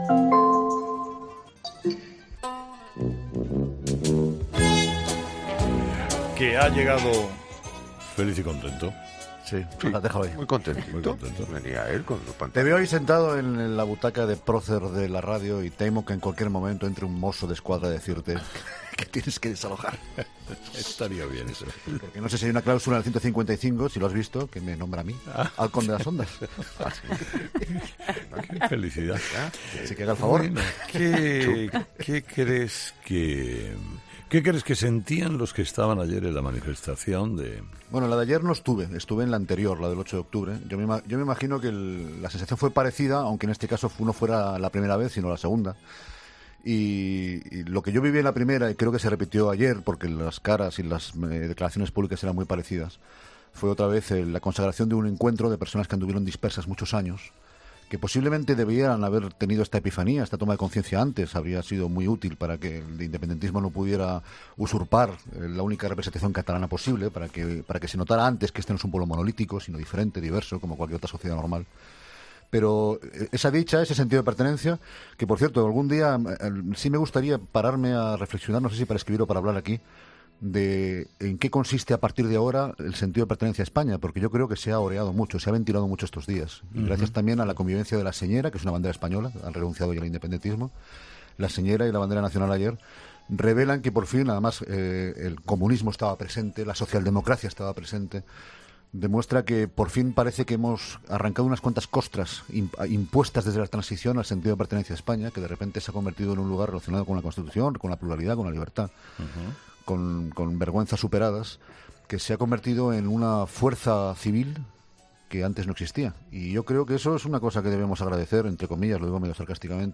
Incluye la entrevista